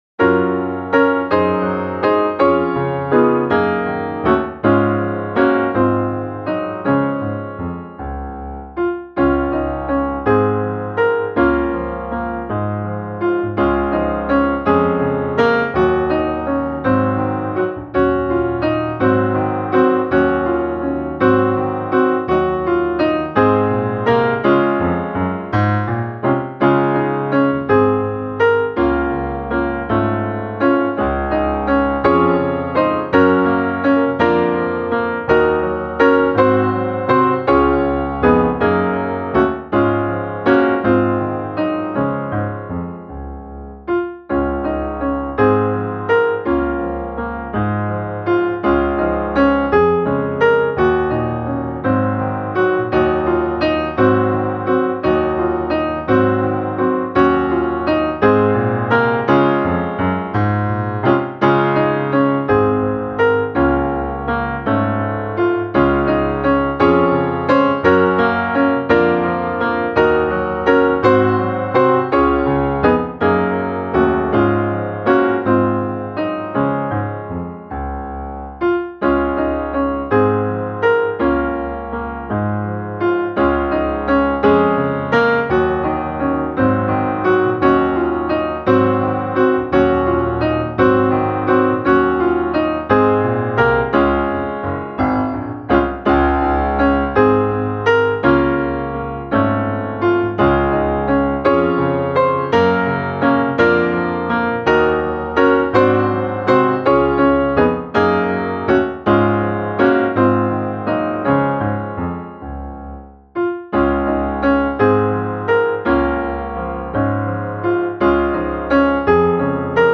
De kommer från öst och väst - musikbakgrund
Gemensam sång
Musikbakgrund Psalm